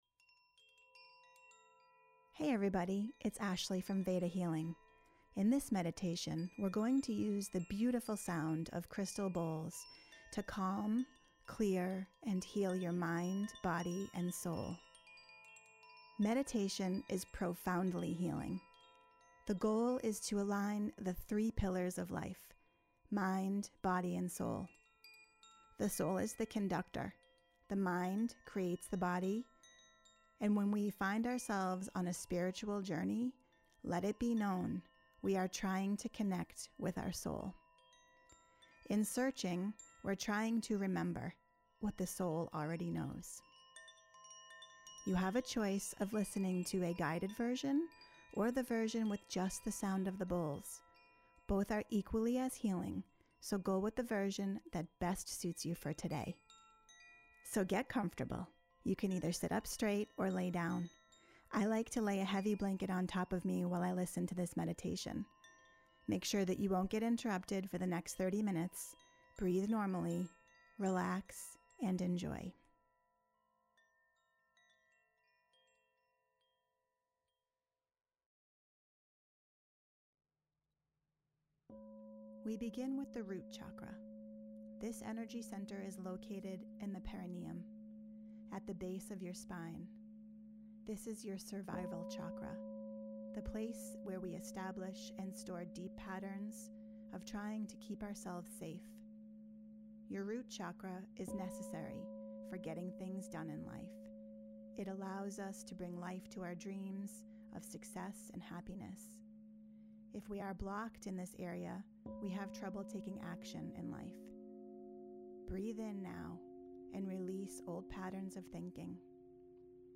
Veda_Bowl_Session_Narrated
Veda_Bowl_Session_Narrated.mp3